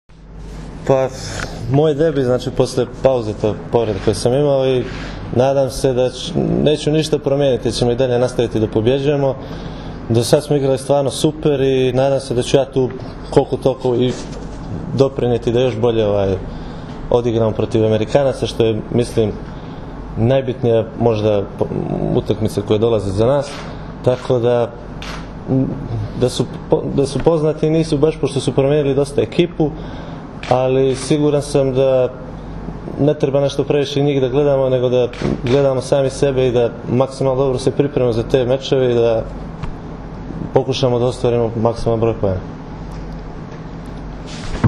IZJAVA MILOŠA NIKIĆA